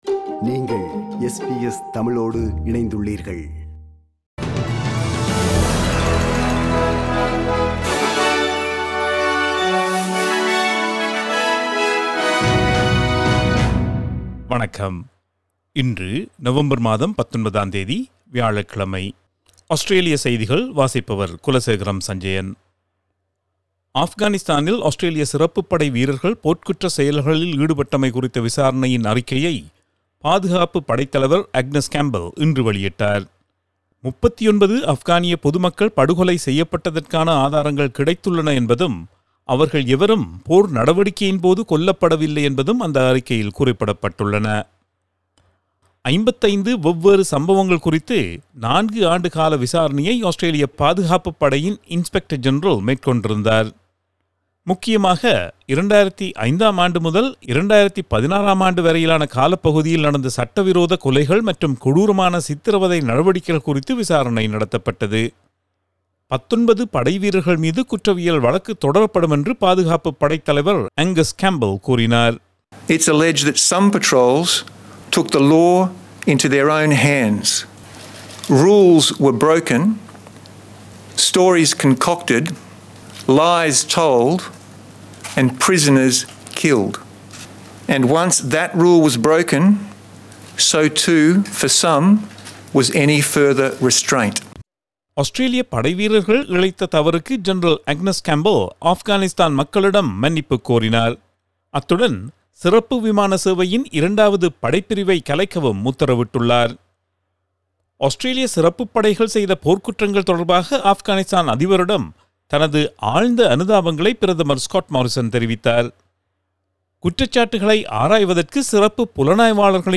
Australian news bulletin for Thursday 19 November 2020.